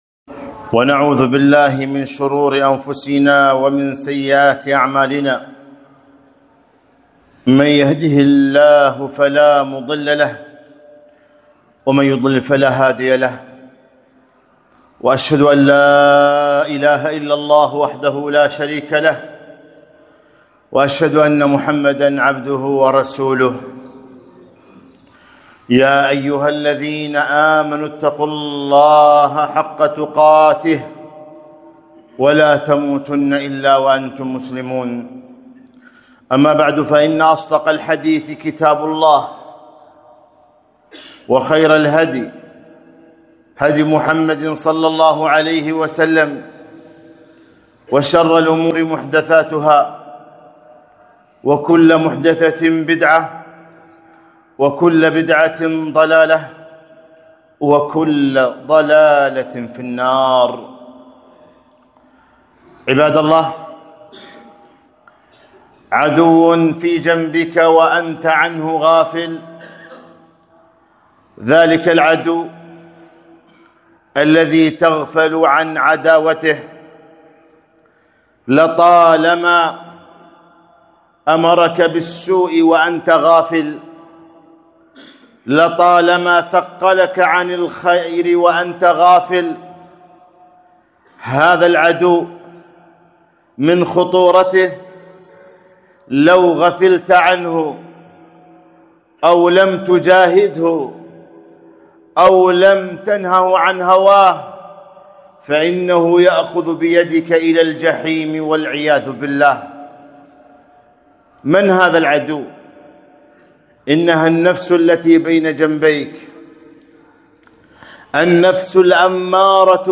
خطبة - ( إن النفس لأمارة بالسوء )